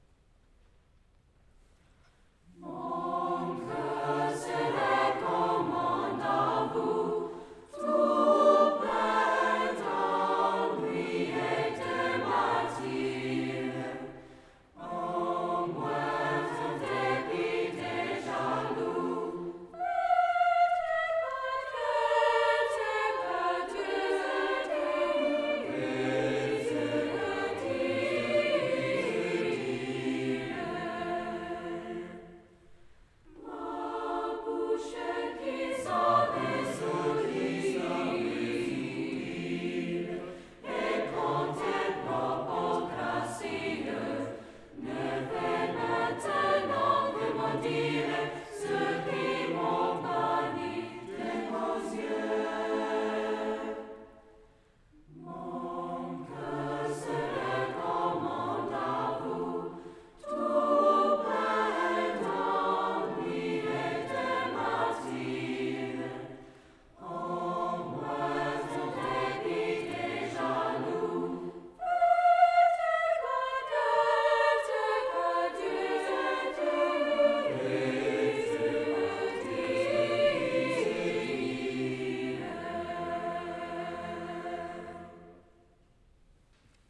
Brookline High School Camerata
Sunday, March 18, 2012 • United Parish, Brookline, MA